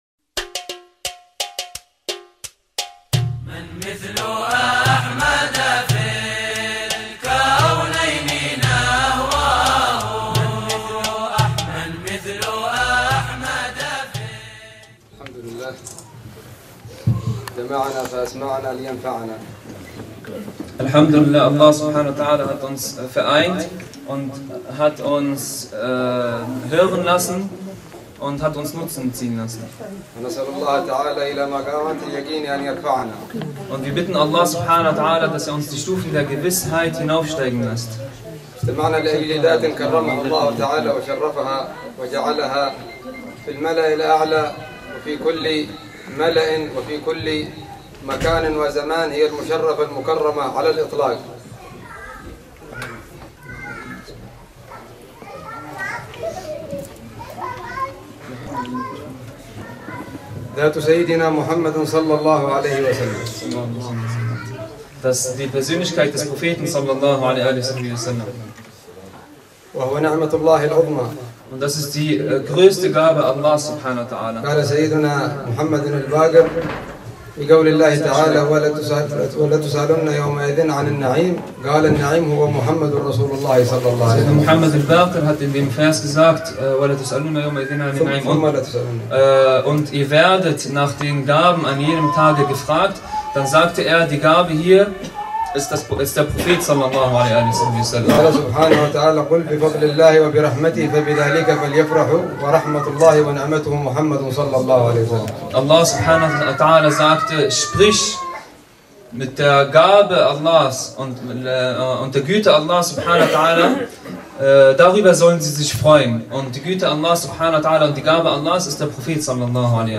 Veranstaltungsort war haupts�chlich die Islamische Akademie e.V. Berlin und das HaDeWe (Haus der Weisheit) .